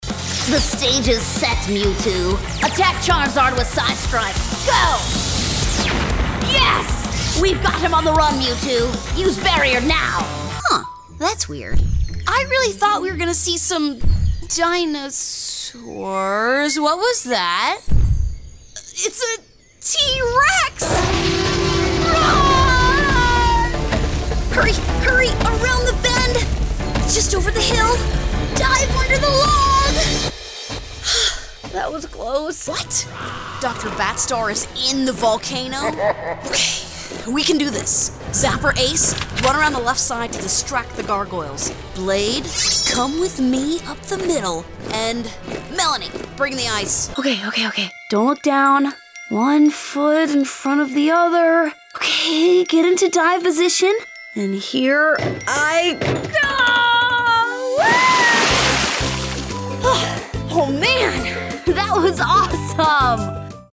English (American)
Commercial, Young, Natural, Friendly, Corporate
Explainer
Young adult, female voice, ages 25 to 45. North American, English speaker. Conversational, authentic, and believable voice.